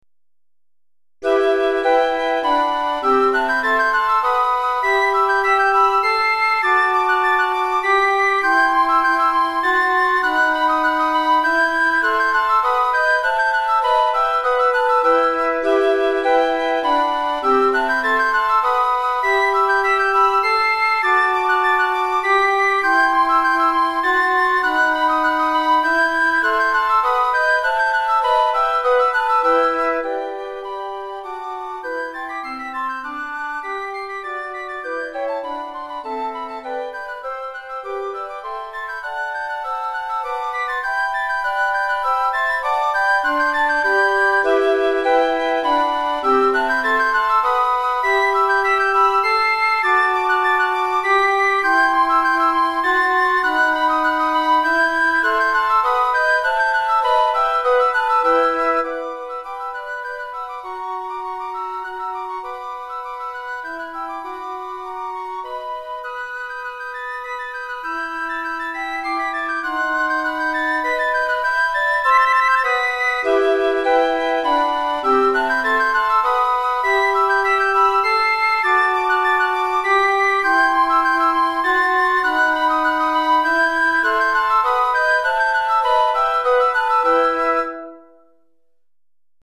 Piccolo et 3 Flûtes